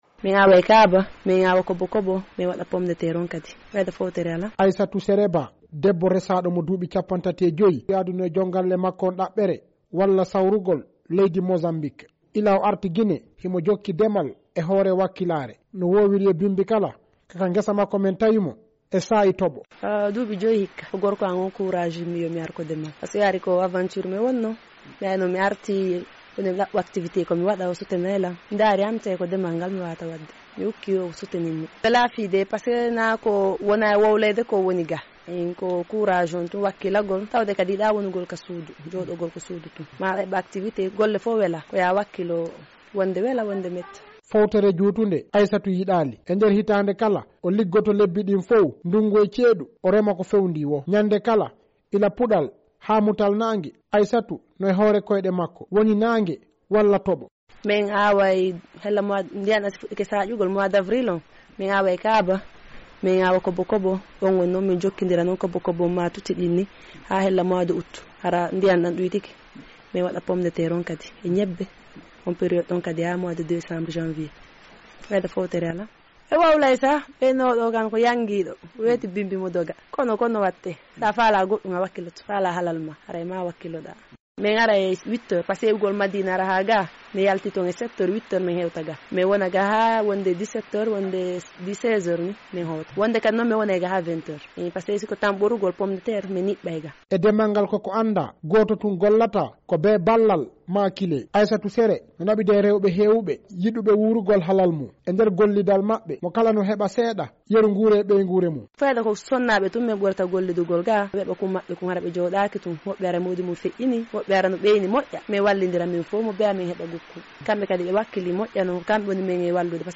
Gine: reportaas fii debbo remoowo ka nokkuure Timbi Madina